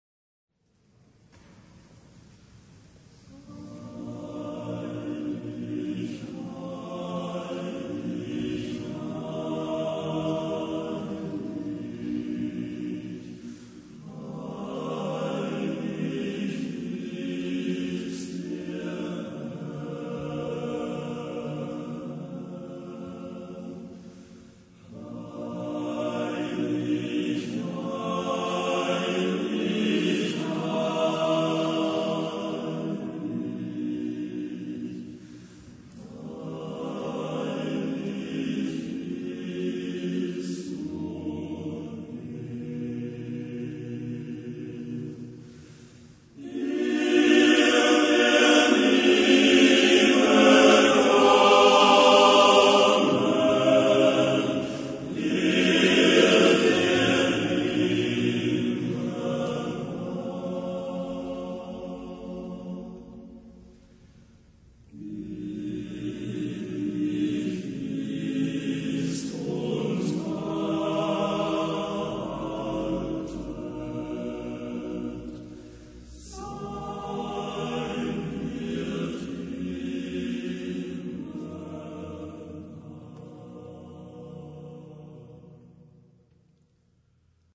Genre-Style-Forme : Sacré ; Romantique ; Messe
Type de choeur : TTBB  (4 voix égales d'hommes )
Tonalité : si bémol majeur